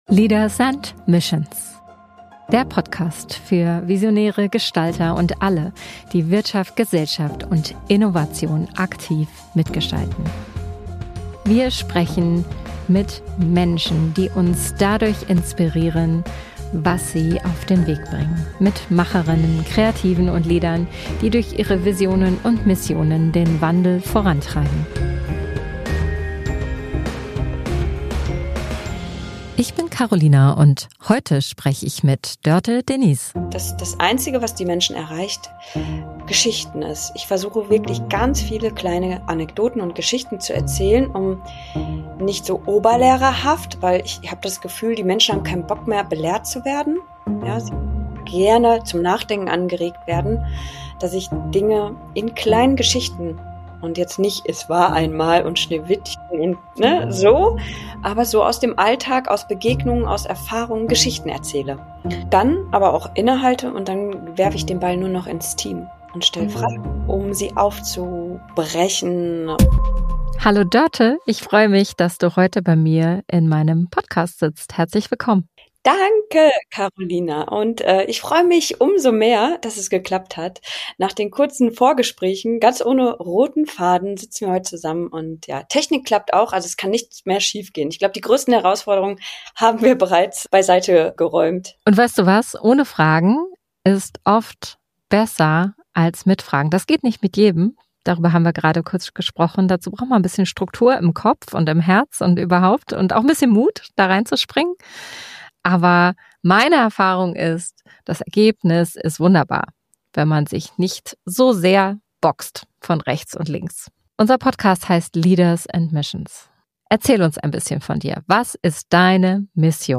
Ein Gespräch über Mut, Business mit Sinn...